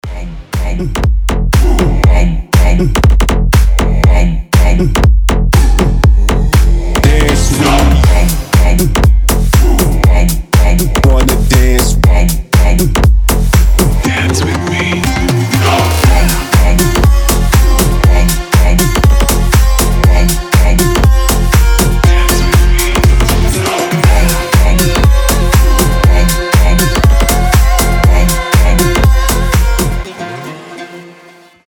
• Качество: 320, Stereo
громкие
мощные басы
восточные
G-House